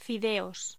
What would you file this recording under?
Locución: Fideos voz